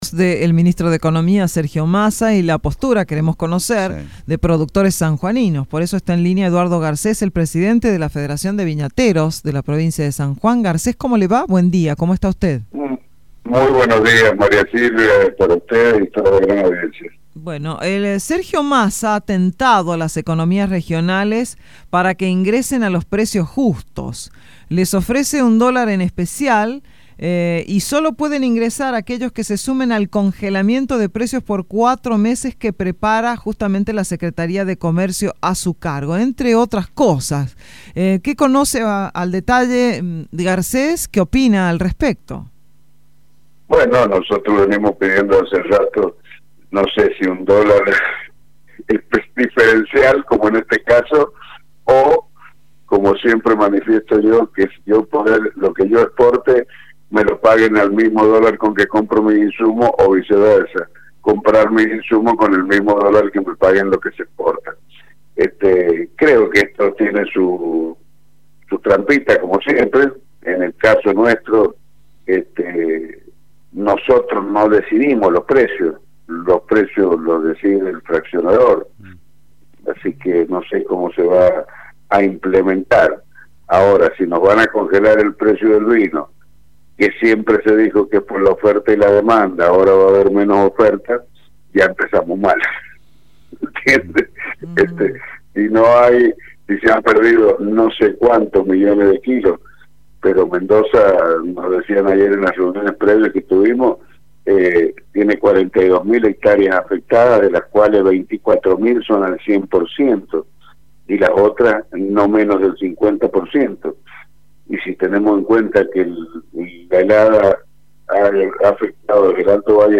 dialogó con los periodistas de Radio Sarmiento acerca de esto y se mostró no muy satisfecho.